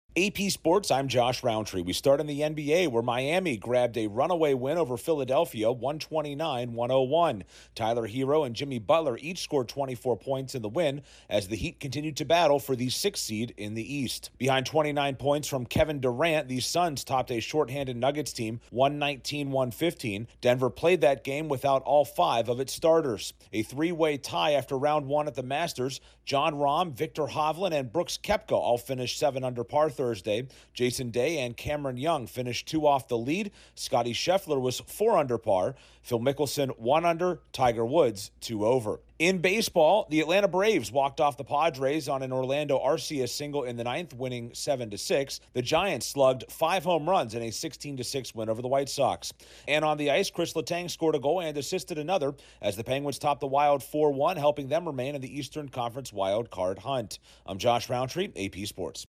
The Heat and Suns pick up big wins, a three-way tie at the Masters, the Braves walk off the Padres, the Giants smash five home runs and the Penguins grab a key win. Corespondent